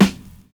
VINYL 15 SD.wav